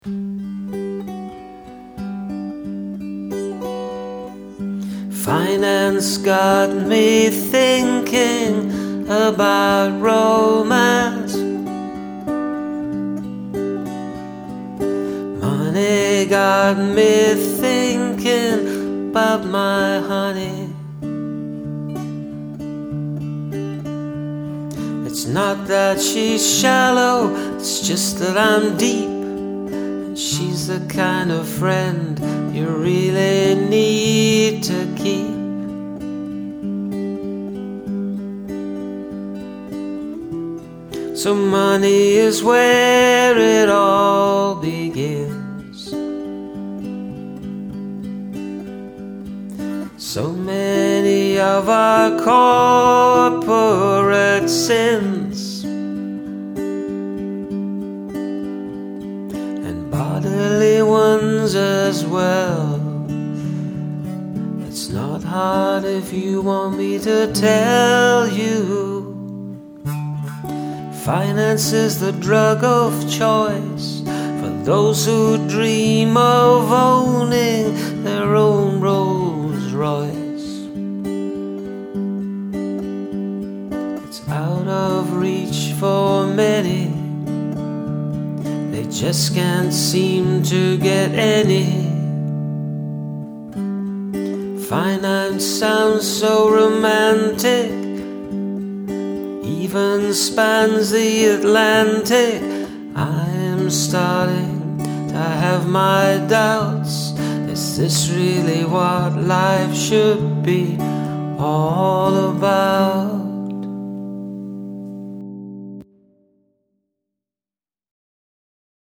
Reflective music and story that draw the listener in.